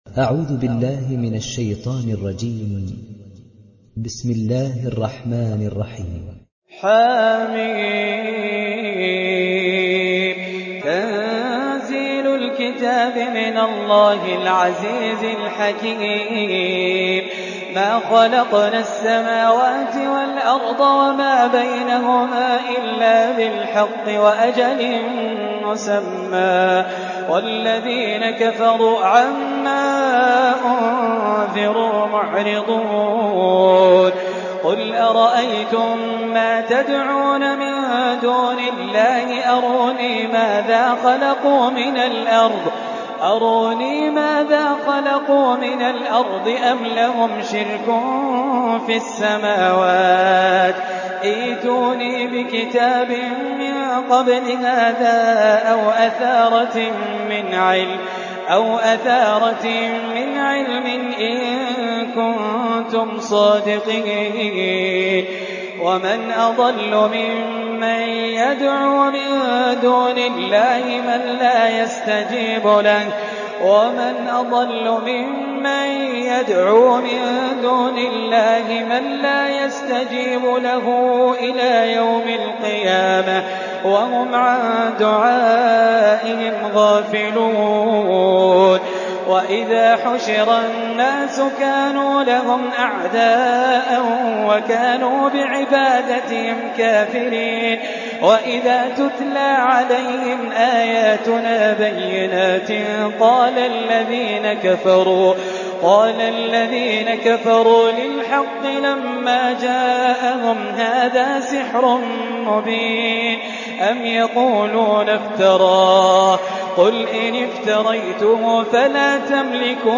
تحميل سورة الأحقاف mp3 بصوت خالد الجليل برواية حفص عن عاصم, تحميل استماع القرآن الكريم على الجوال mp3 كاملا بروابط مباشرة وسريعة